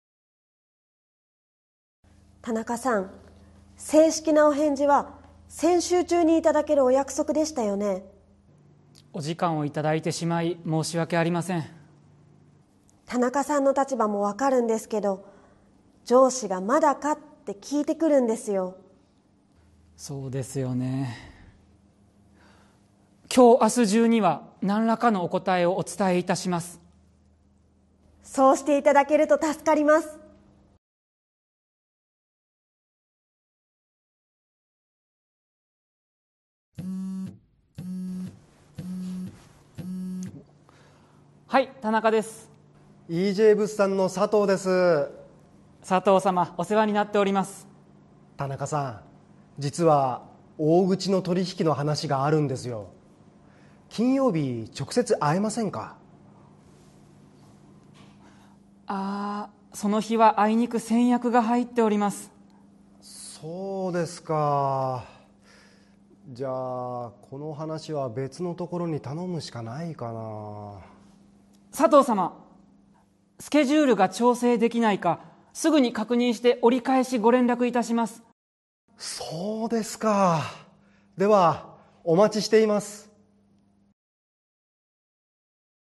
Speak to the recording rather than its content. This is the final review lesson that revisits important phrases and situations from the series. It features two workplace scenarios demonstrating essential business Japanese expressions for handling delays and schedule conflicts.